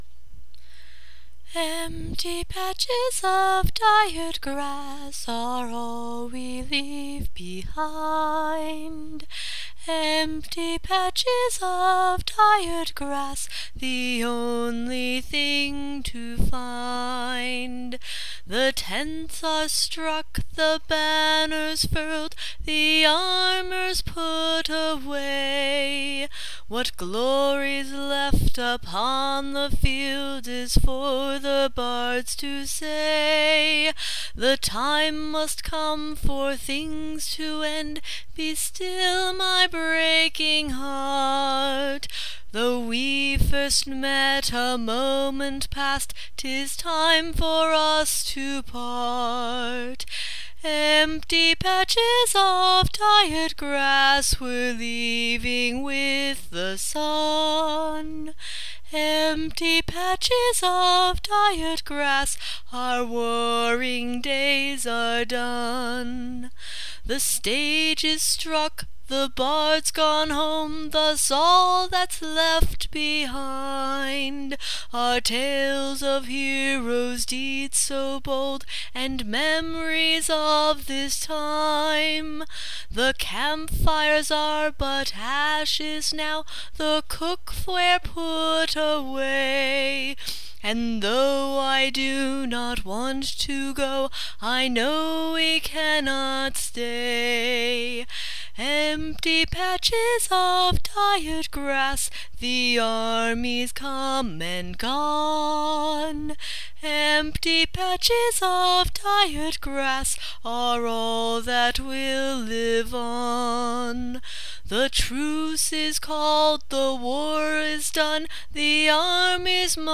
This is a sort of melancholy song for me, and it’s become a tradition for me to sing it on the last night of Pennsic before we head home, even if it is only to myself.